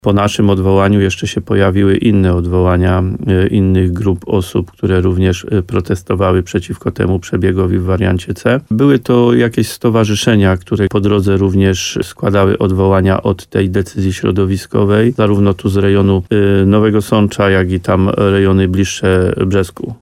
Jak powiedział w programie Słowo za Słowo w radiu RDN Nowy Sącz wójt gminy Łososina Dolna Adam Wolak, w międzyczasie przybyli nowi zwolennicy tej propozycji, którzy też odwołali się do Generalnej Dyrekcji Dróg Krajowych i Autostrad.